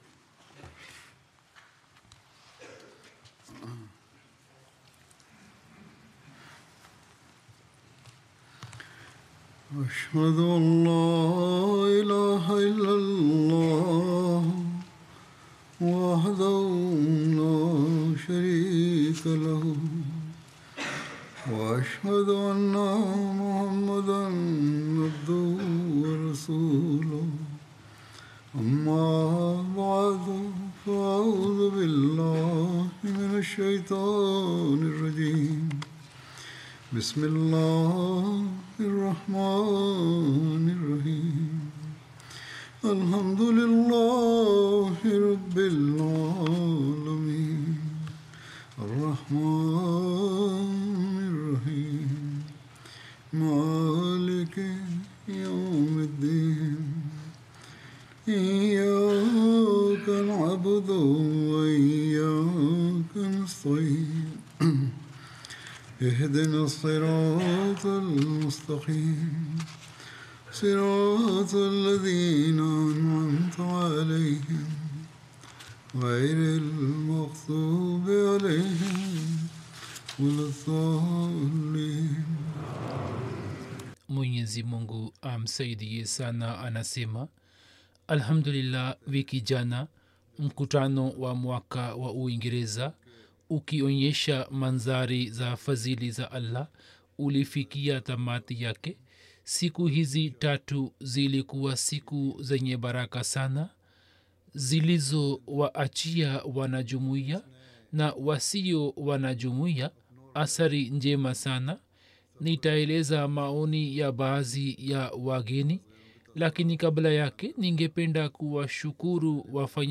Swahili translation of Friday Sermon delivered by Khalifa-tul-Masih on August 2nd, 2024 (audio)